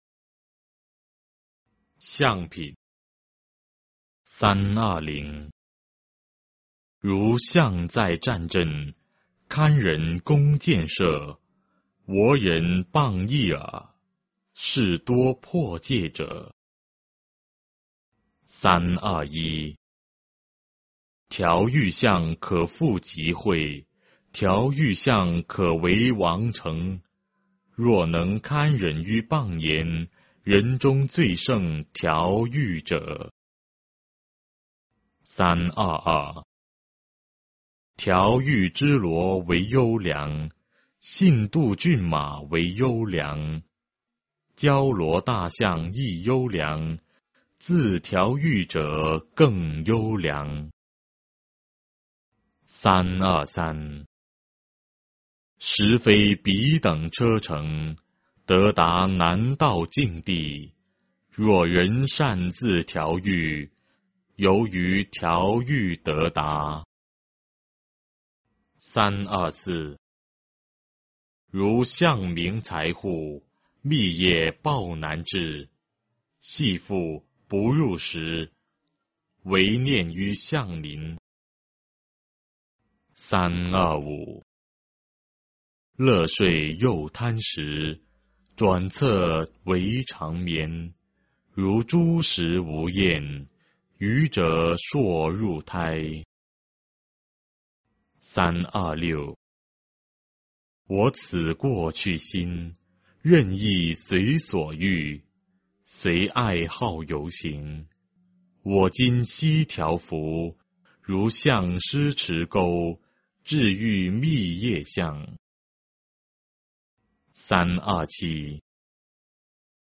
法句经-象品 诵经 法句经-象品--未知 点我： 标签: 佛音 诵经 佛教音乐 返回列表 上一篇： 金光明经05 下一篇： 法句经-心品 相关文章 南无观音菩萨圣号（闽南语）--未知 南无观音菩萨圣号（闽南语）--未知...